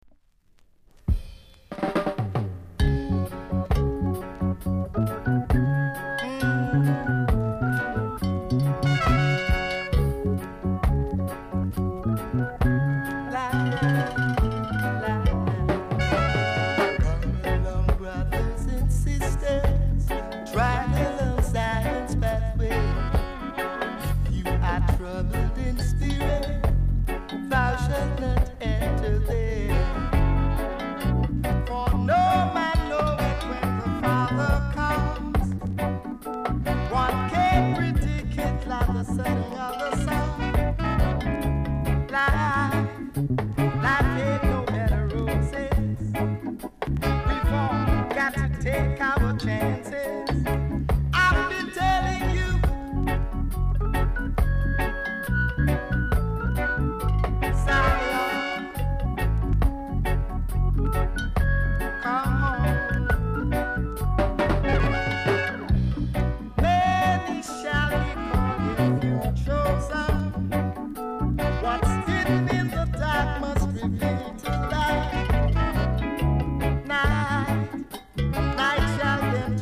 ※小さなチリ、パチノイズが全体的に所々あります。盤は細かい薄い擦り傷がややありますが、見た目ほど音は悪くないです。